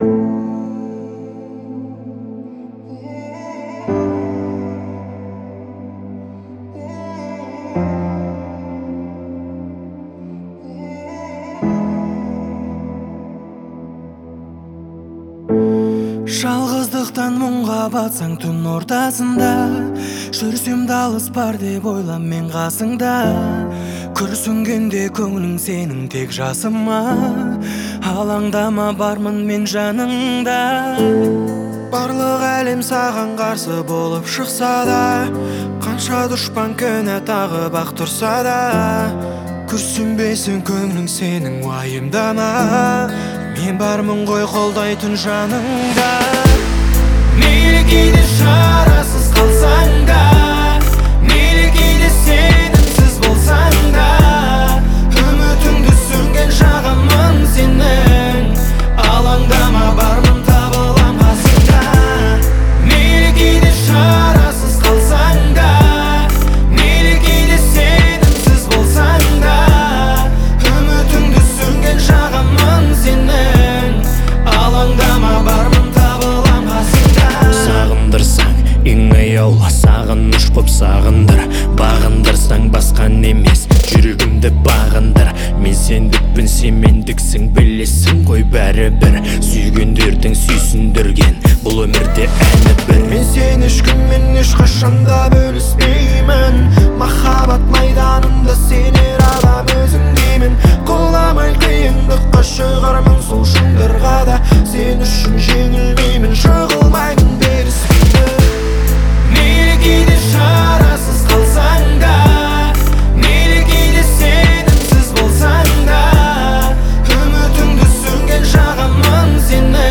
наполнена энергией и позитивом.